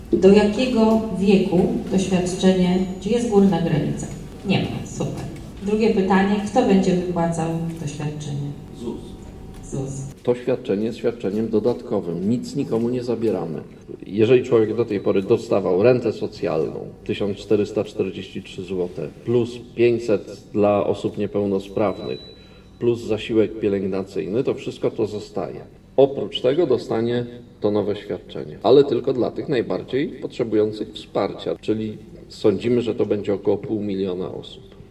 Dodatkowe świadczenie dla pełnoletnich z niepełnosprawnościami O wypłatach nowego świadczenia wspierającego dla pełnoletnich z niepełnosprawnościami mówił w Sieradzu Pełnomocnik Rządu ds. Osób Niepełnosprawnych Paweł Wdówik .
Wiceminister Rodziny i Polityki Społecznej precyzował założenia ustawy odpowiadając na pytania mieszkańców Sieradza, lokalnych organizacji pozarządowych i przedstawicieli miejskich i powiatowych instytucji pomocowych.